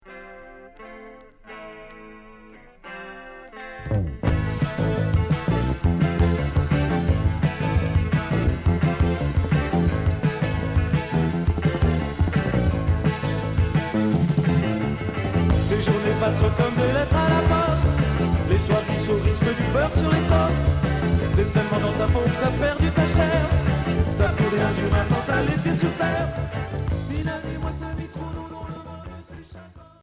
Rock new wave Unique 45t